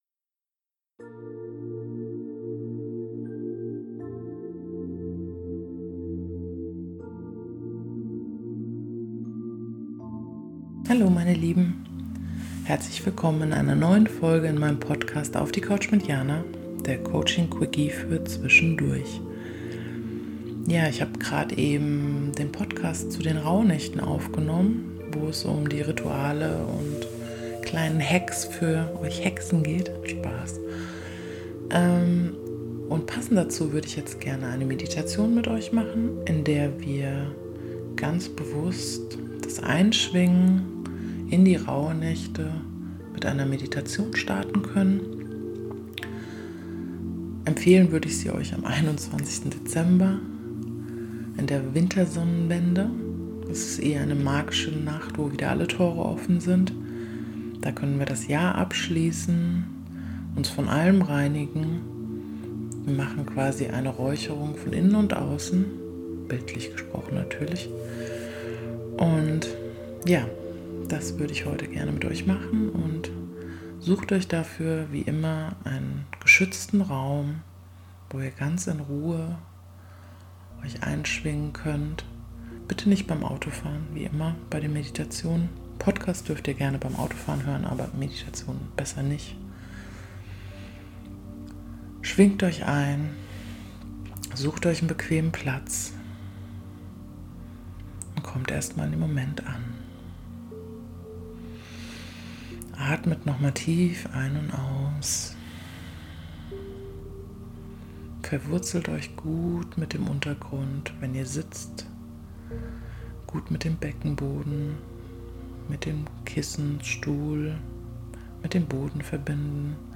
Rauhnacht_Meditation.mp3